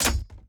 Sword Blocked 1.ogg